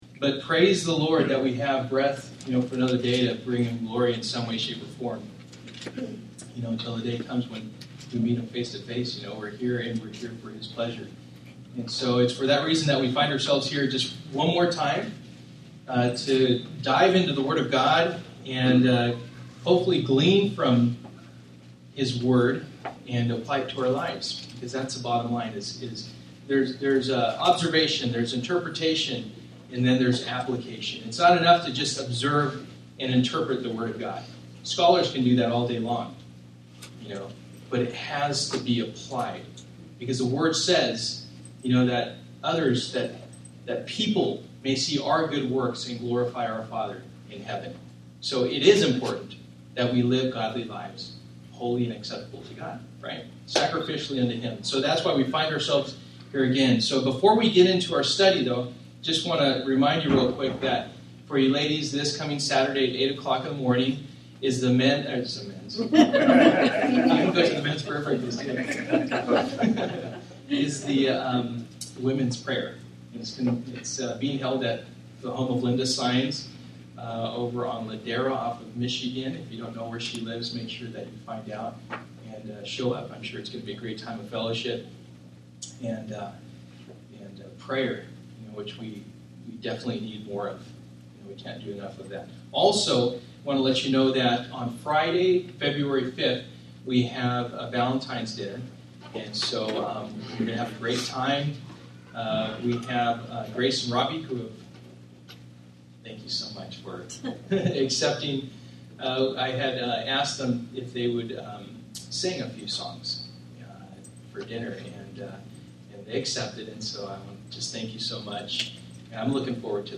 Passage: Acts 4:1-37 Service: Wednesday Night